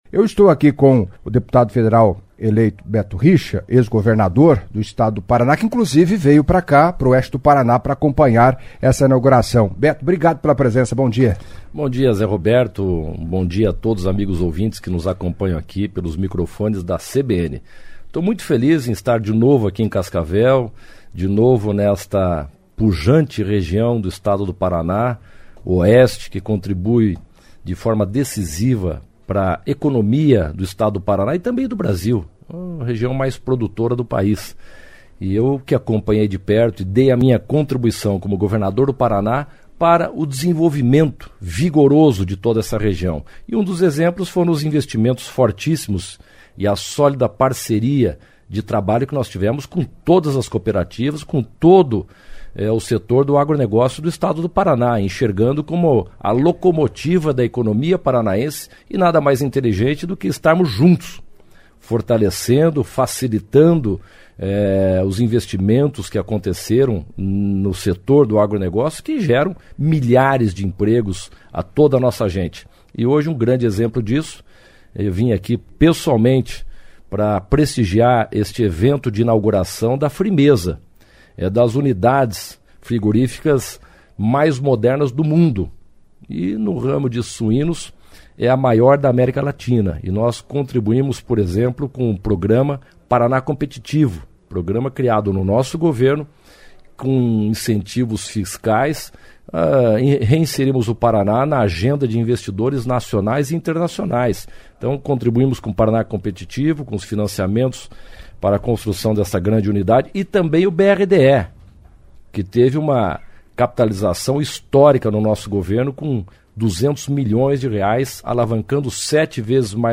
Em entrevista à CBN Cascavel nesta terça-feira (13) o deputado federal eleito, Beto Richa, do PSDB, inicialmente, falou da vinda à região Oeste do Paraná, além de outros compromissos, irá prestigiar a inauguração do frigorífico de abate de suínos da Frimesa, o maior da America Latina, solenidade marcada para às 17h desta terça-feira (13), em Assis Chateaubriand.